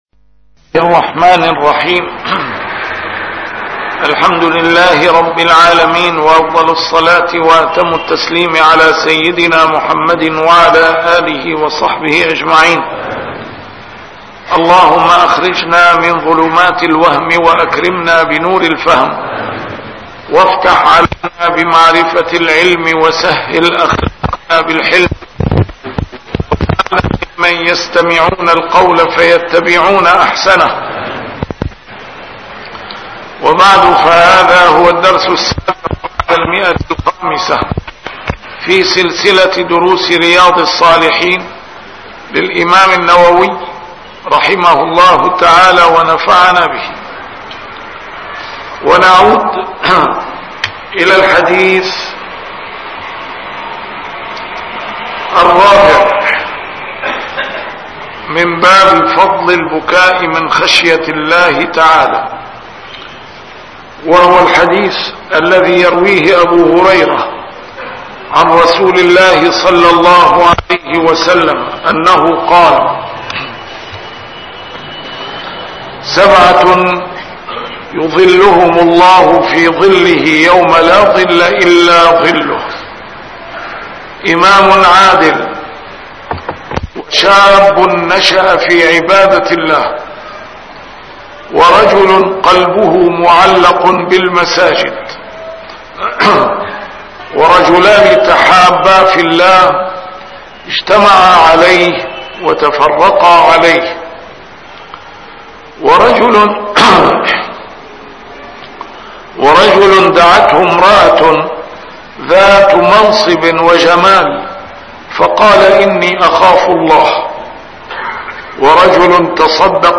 A MARTYR SCHOLAR: IMAM MUHAMMAD SAEED RAMADAN AL-BOUTI - الدروس العلمية - شرح كتاب رياض الصالحين - 507- شرح رياض الصالحين: البكاء